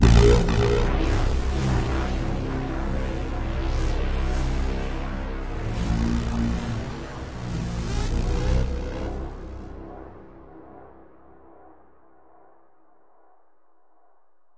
描述：1) VSTi Elektrostudio Model Pro + reverb + flanger + delay + equalizer + exciter2) VSTi Elektrostudio Model Mini + delay + flanger
标签： Model vsti effect Elektrostudio Pro sfx Mini
声道立体声